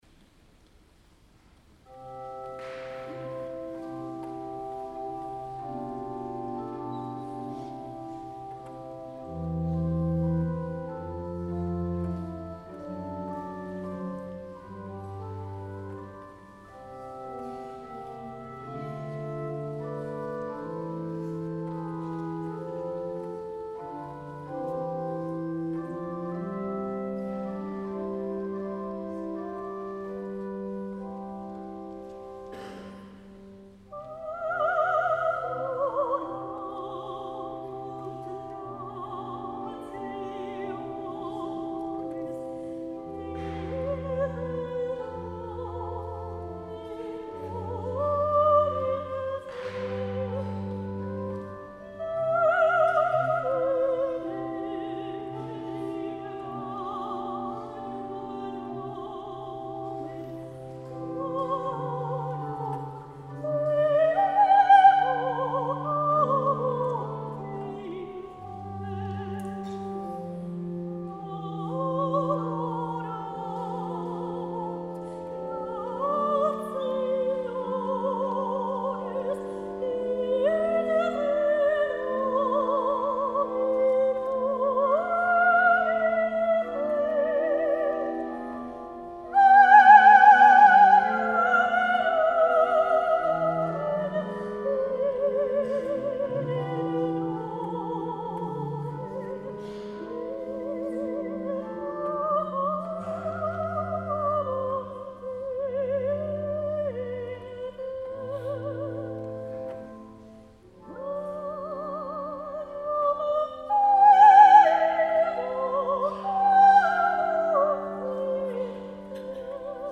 S. Gaudenzio church choir Gambolo' (PV) Italy
22 dicembre 2025 - Concerto di Natale
audio del concerto